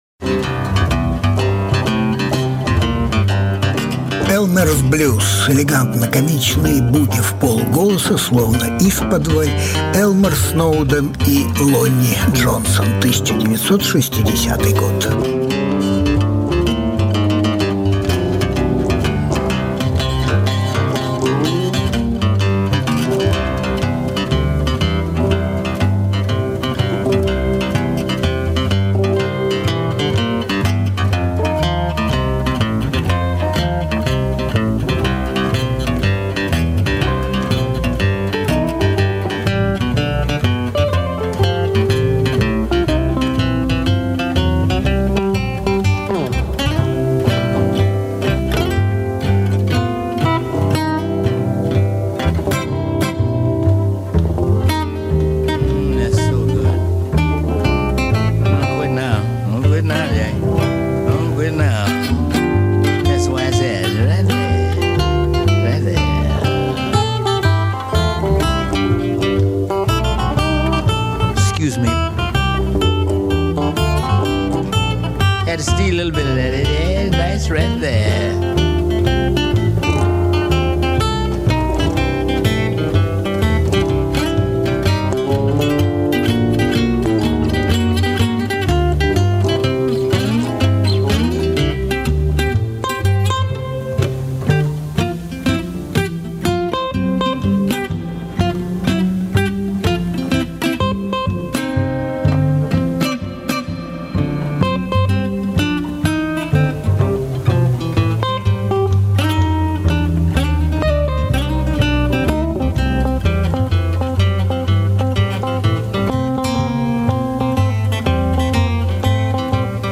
Elmer Snowden - играет на банджо и гитаре. Chris Farlowe - певец.
Альбом: Различные альбомы Жанр: Блюз СОДЕРЖАНИЕ 05.10.2020 Гитарист и мастер игры на банджо Elmer Snowden родился 120 лет назад - 9 октября 1900 года в Балтиморе (штат Мэрилэнд). 1.